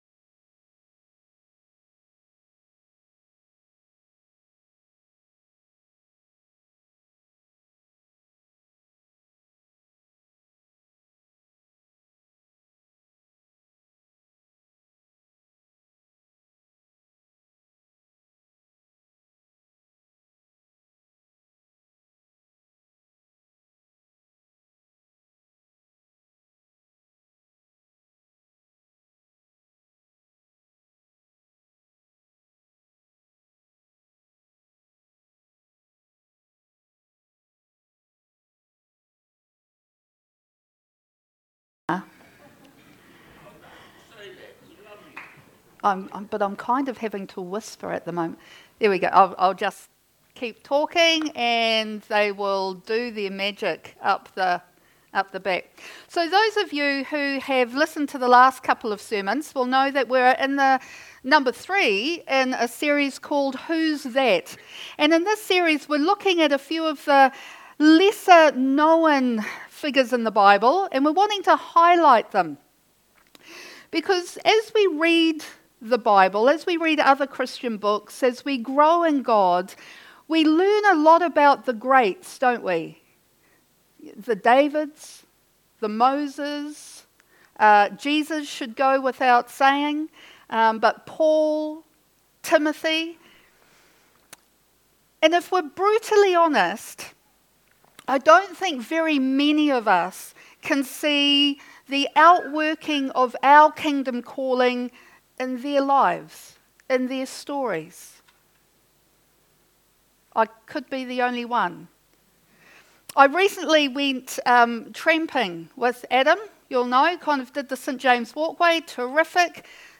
Sermon#3 in the series Whos's That